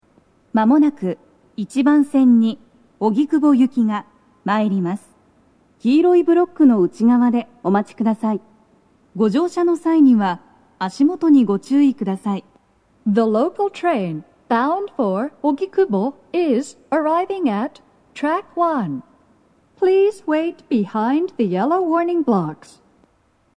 スピーカー種類 BOSE天井型
鳴動は、やや遅めです。
１番線 荻窪・方南町方面 接近放送 【女声